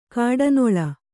♪ kāḍanoḷa